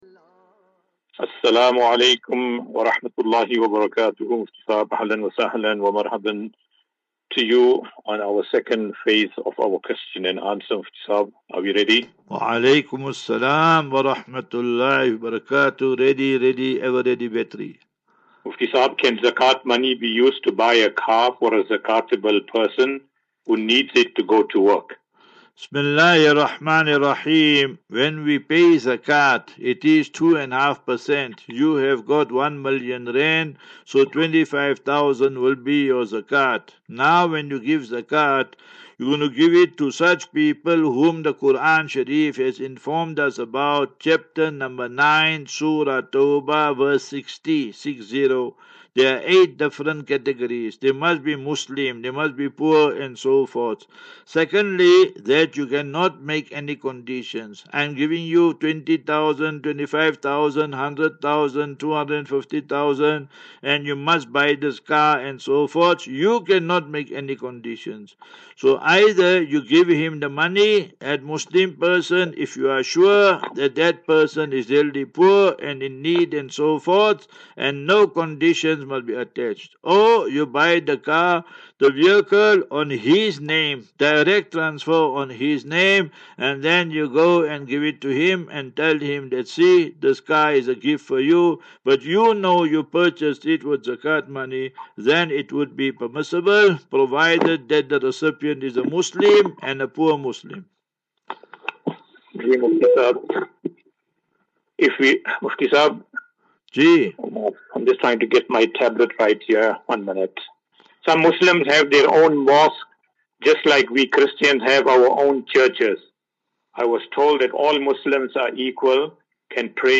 View Promo Continue Install As Safinatu Ilal Jannah Naseeha and Q and A 8 Apr 08 April 2024.